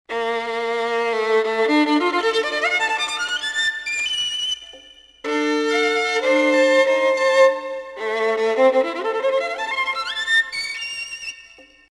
12.1. слайд. скрипка сольнПассажи